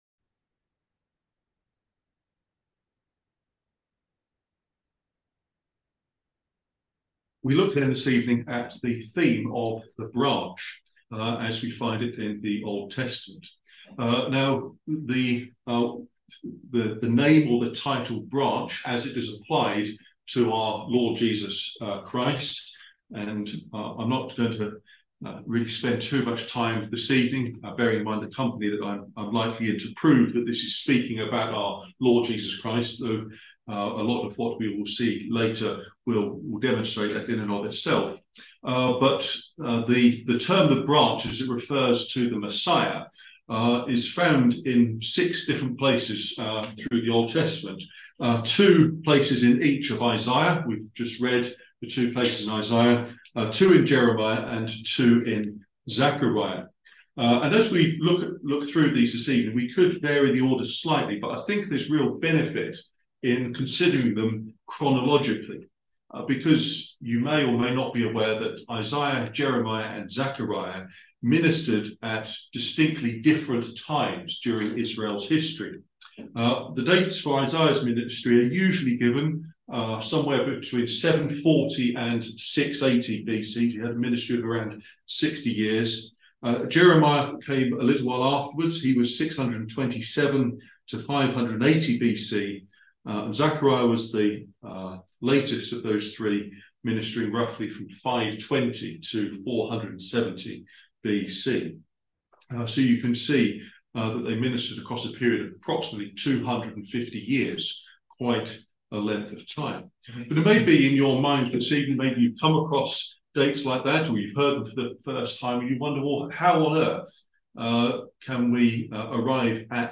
Wednesday Bible Study The Branch